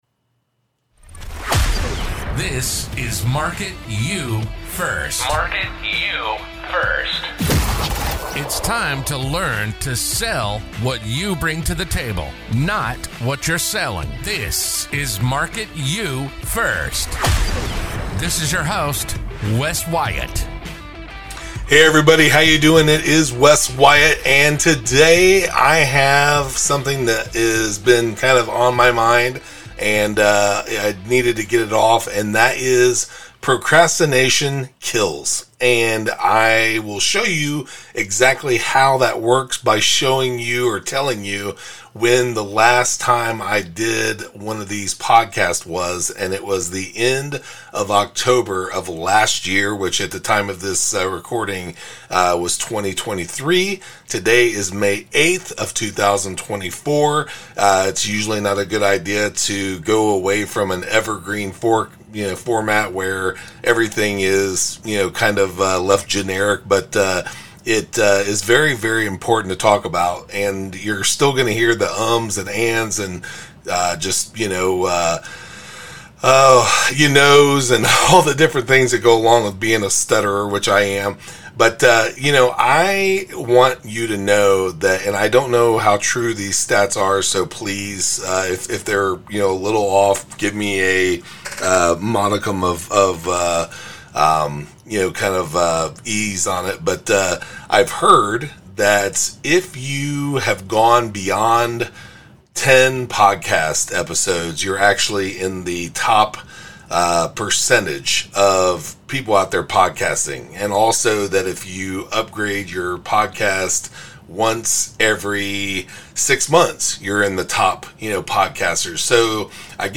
So here I am, stutter and all, ready to get down to the nitty gritty.